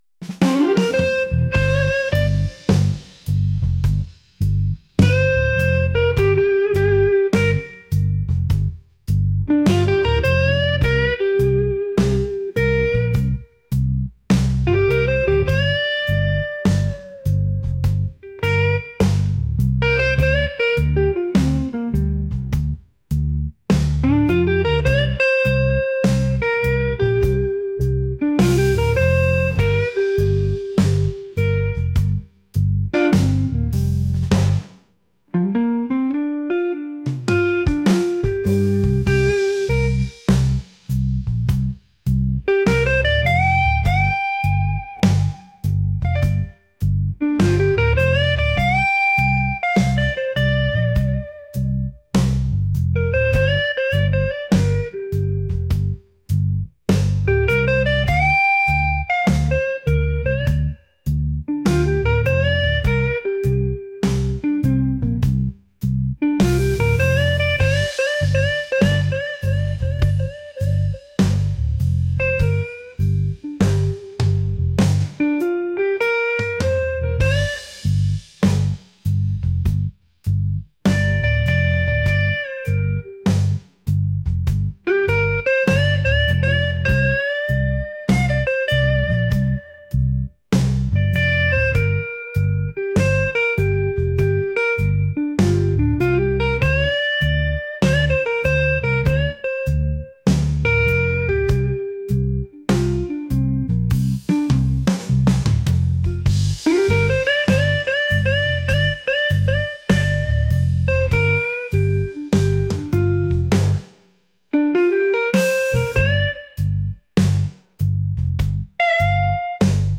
soulful | blues